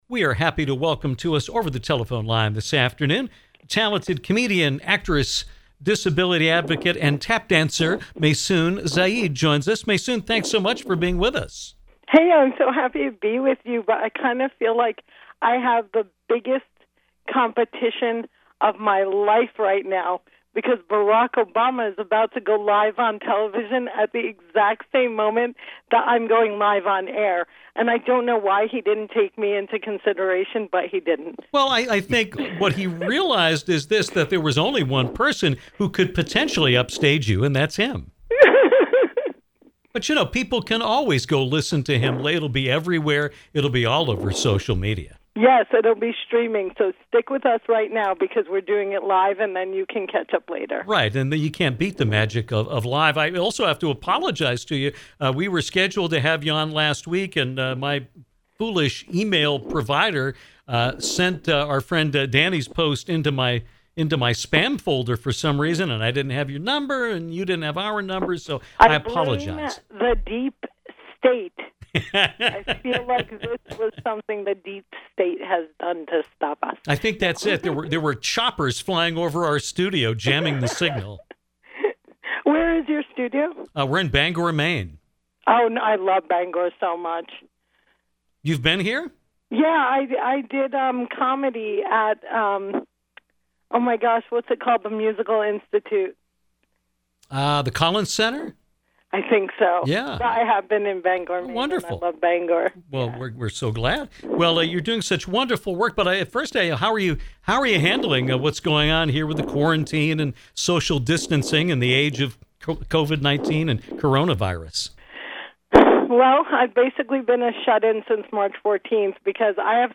Comedian and author Maysoon Zayid talked with us about her career and how disabled people like herself are dealing with Coronavirus.